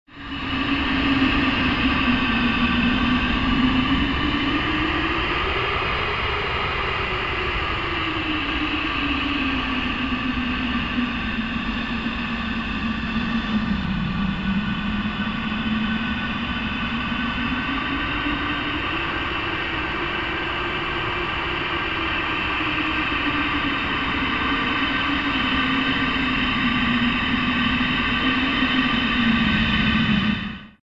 Звуки ветра в пустыне
Шум бушующего песчаного ветра в пустыне